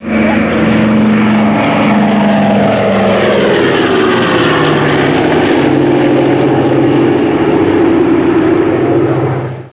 Pratt & Whitney R-1830-90 Twin Wasp
Moteur de 14 cylindres sur deux rangées double étoile sur 2 lignes radiales à valve sur hampe avec clapet en manchon refroidi par air